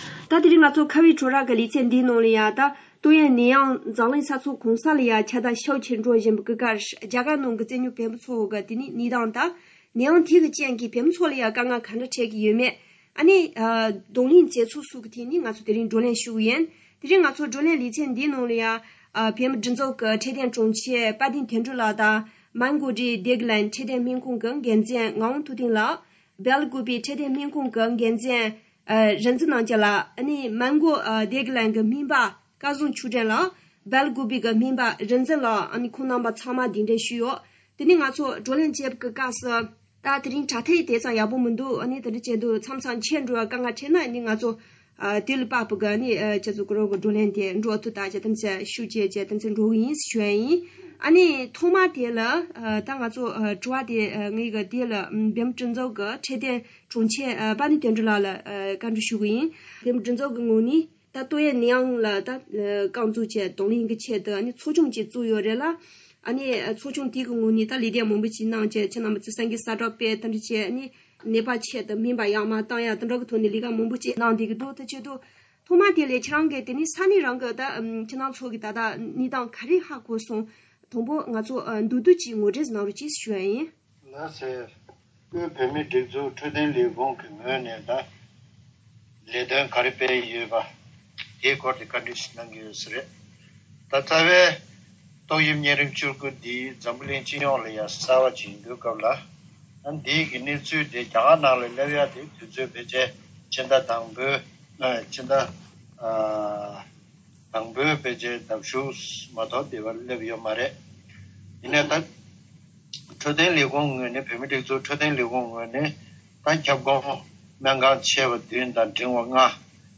འབྲེལ་ཡོད་ལ་བཀའ་འདྲི་ཞུས་ཏེ་ཕྱོགས་སྒྲིག་དང་སྙན་སྒྲོན་ཞུས་པར་གསན་རོགས་ཞུ།།